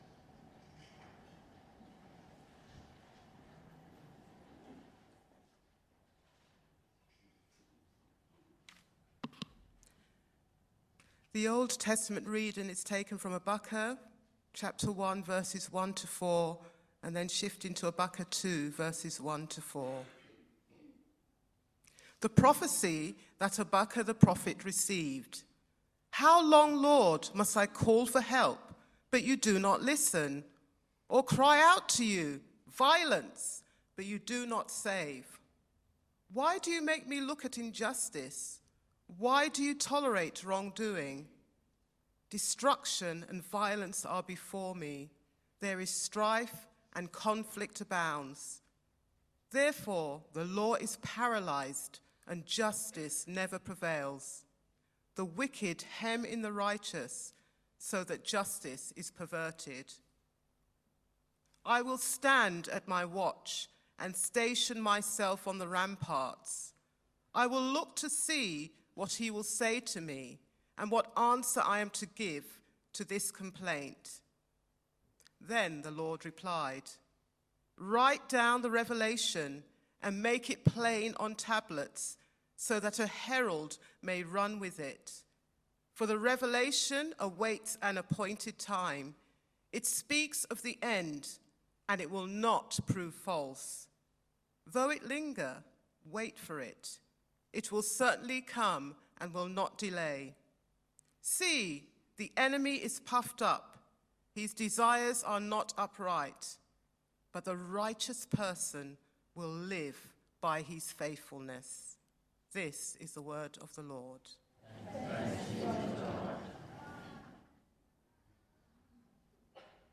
TCE_Sermon-October-5th-2025.mp3